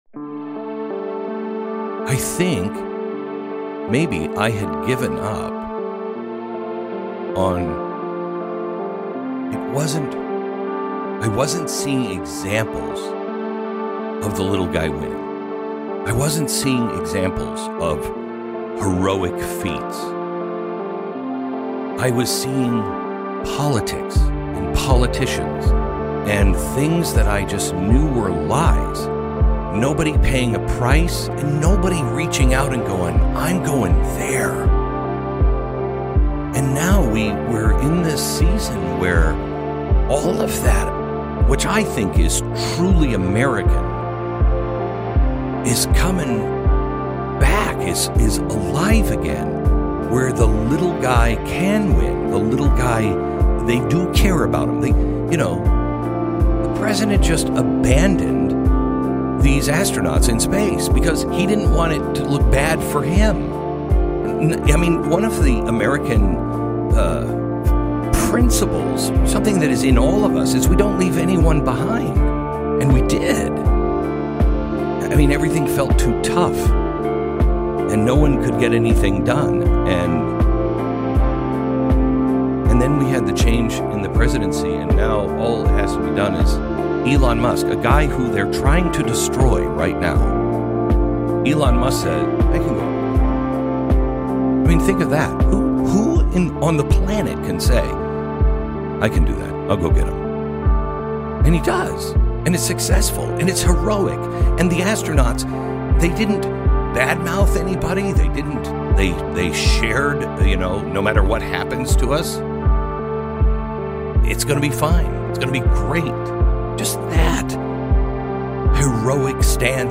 monologue